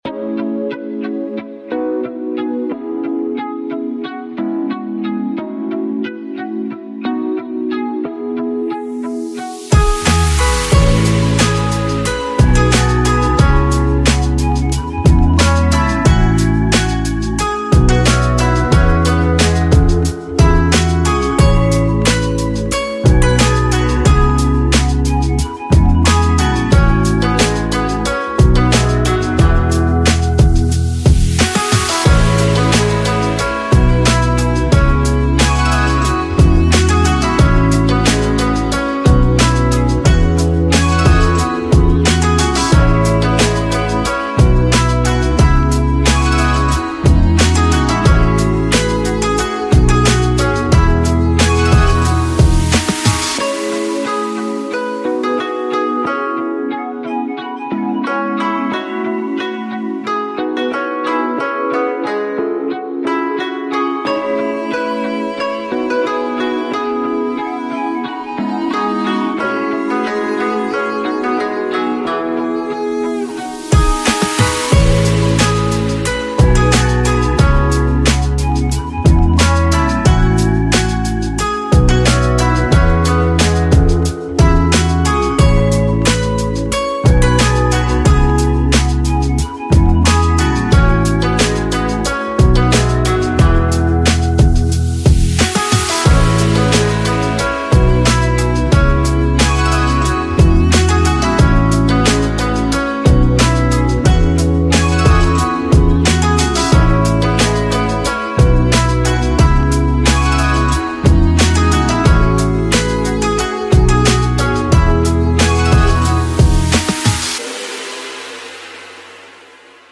Soulful Rhythms of Japanese Hip-Hop Chill Vibes